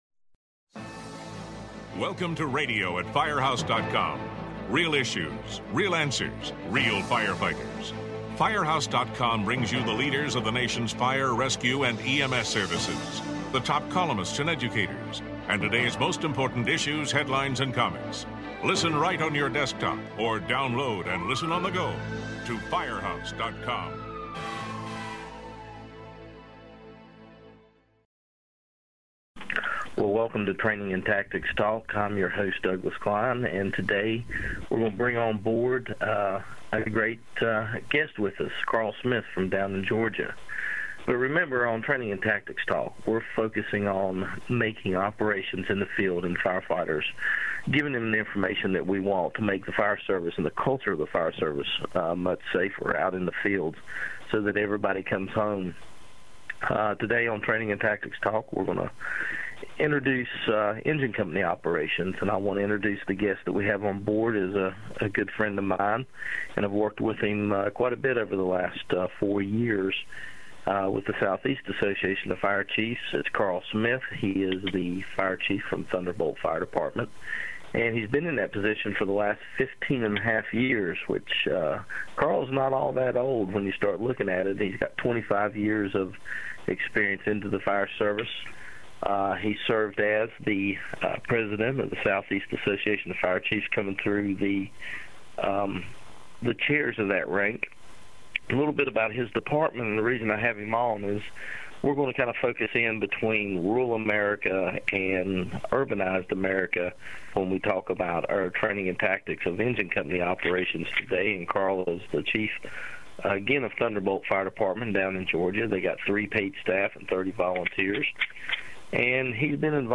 The two talk about size-up skills, apparatus placement and the need to understand a variety of sound tactical skills and safety philosophies. This podcast looks at some of the key operational factors involving smaller departments.